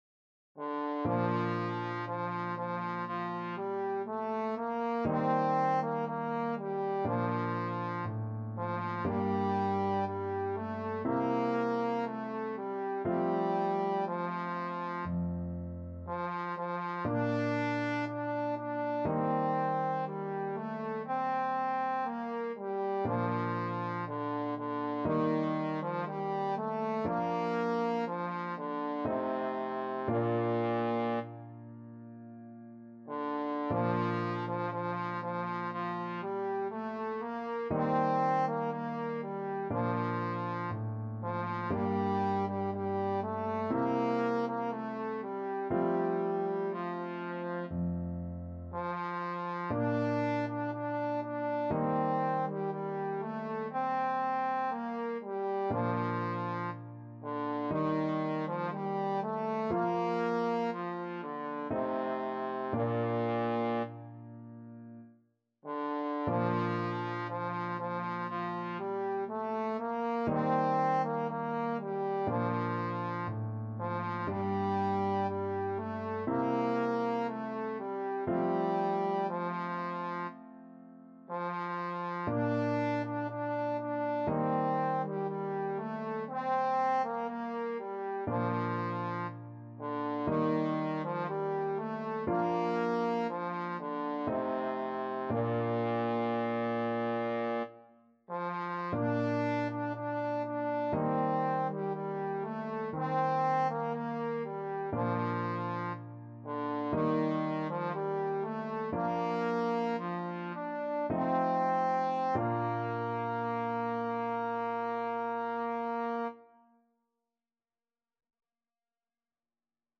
Trombone version
4/4 (View more 4/4 Music)
Bb3-D5
~ = 100 Adagio
Classical (View more Classical Trombone Music)